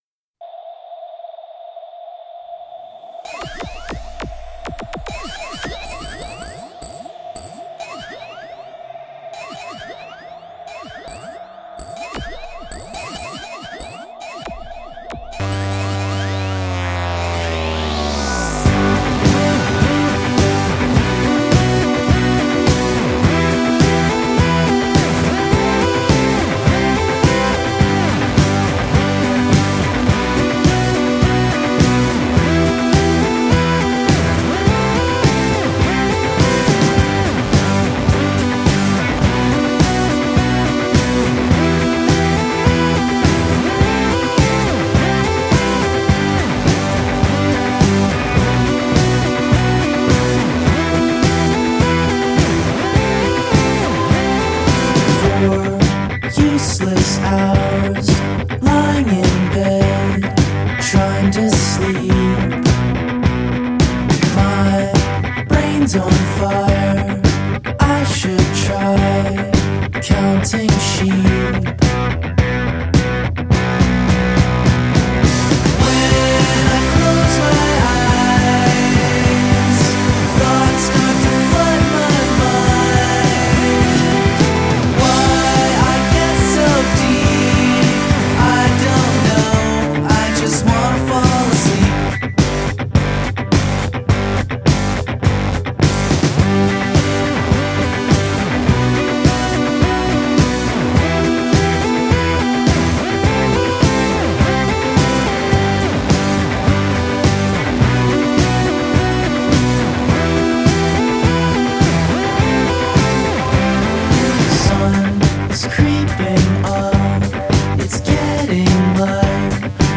You know how some songs just sound better loud?
slithering synth intro